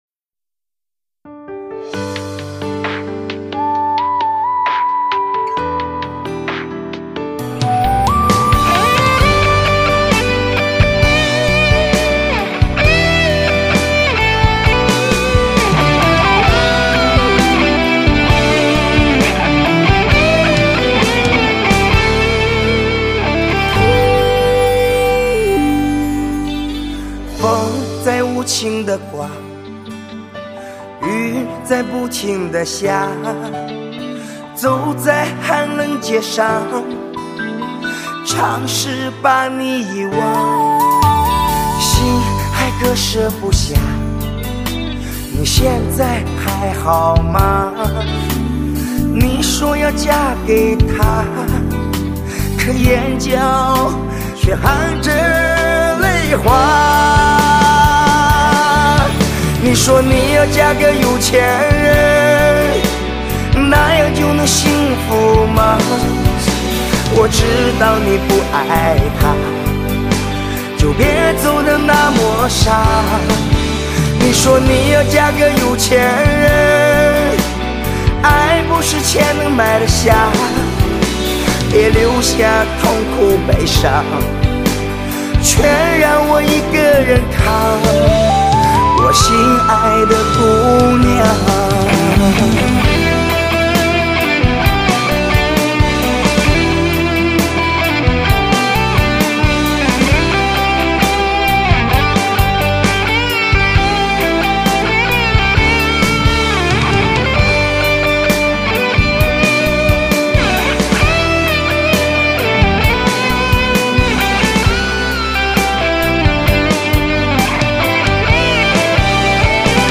封底：一张真正属于男人的伤感情歌大碟
首首伤感情歌金曲精挑细选